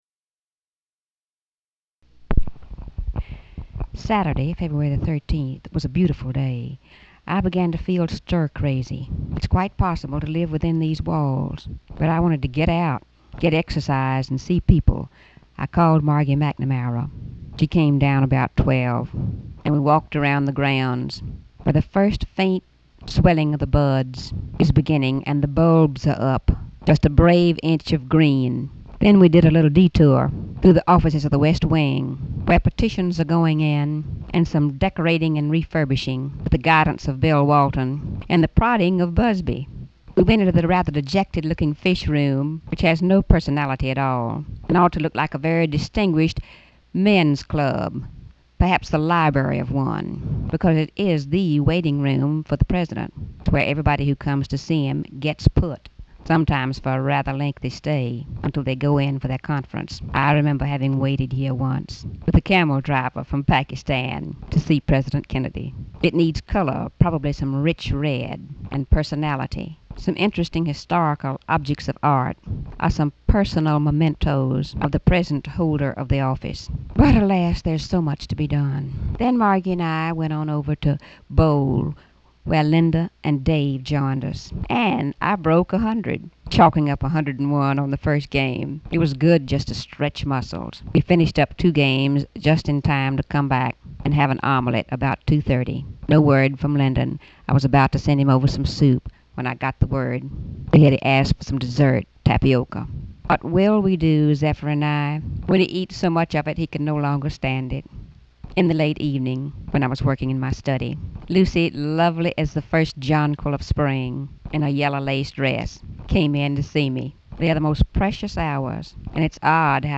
Audio diary and annotated transcript, Lady Bird Johnson, 2/13/1965 (Saturday) | Discover LBJ